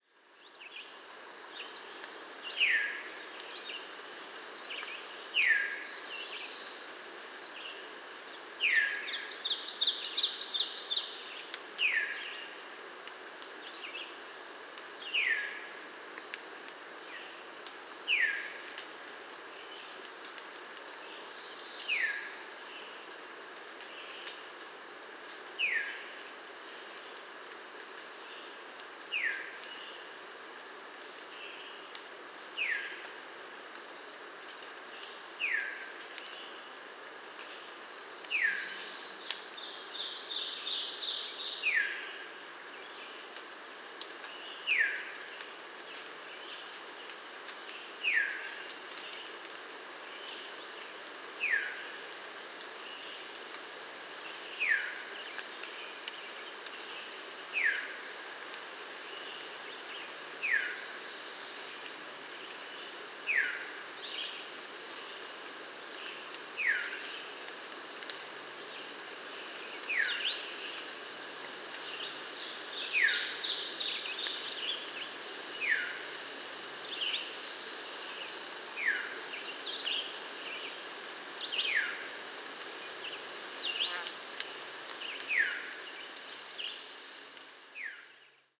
Grive fauve – Catharus fuscescens
Cris ‘Veer’ Parc National du Mont-Orford, QC. 22 juin 2018. 15h00.